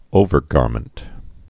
(ōvər-gärmənt)